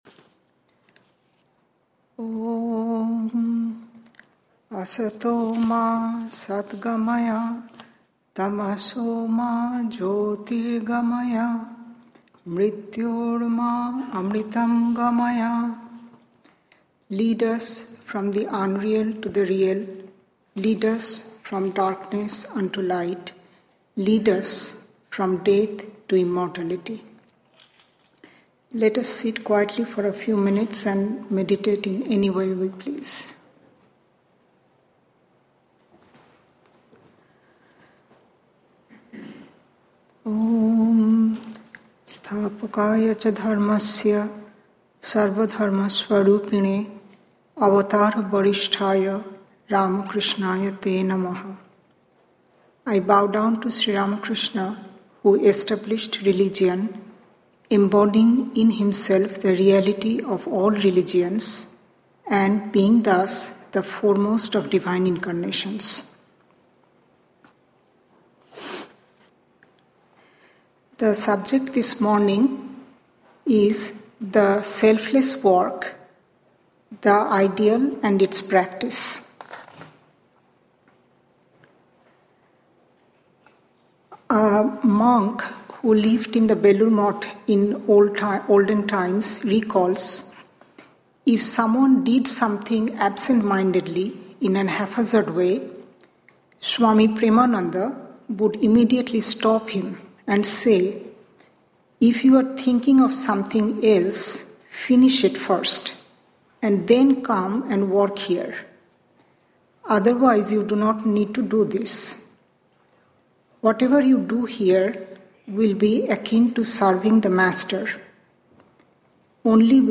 Sunday Lectures